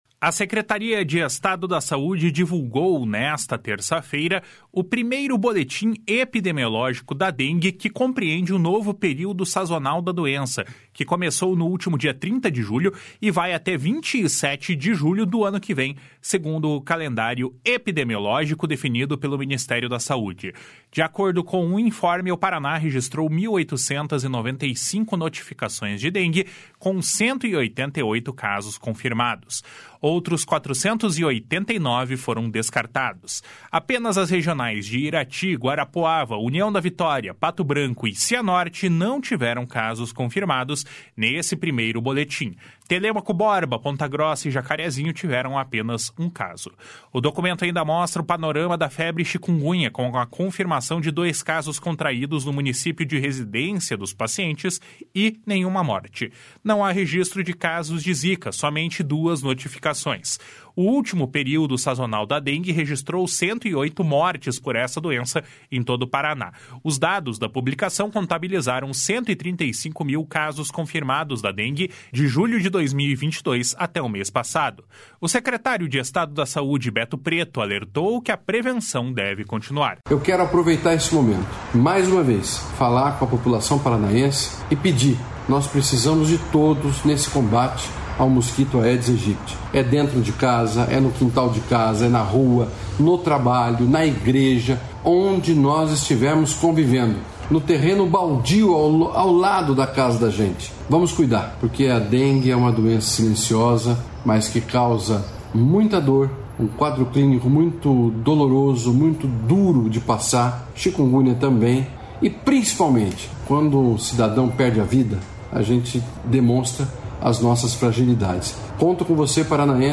BOLETIM DA DENGUE.mp3